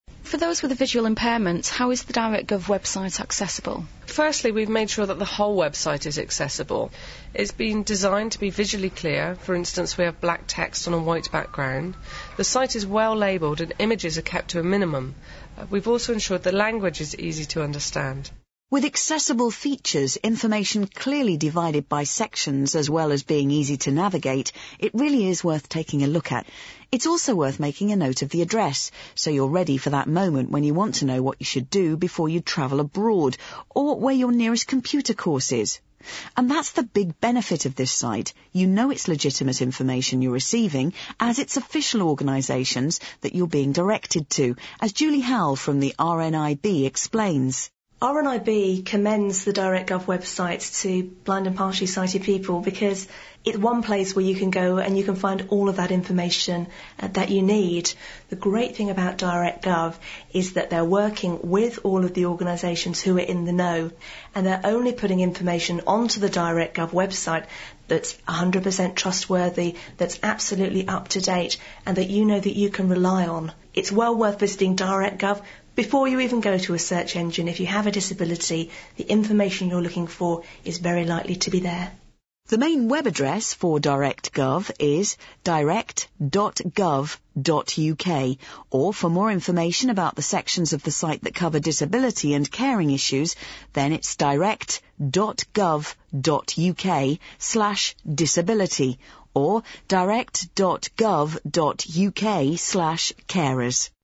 You can hear an audio interview about Cross & Stitch's accessibility by clicking on one of the links below.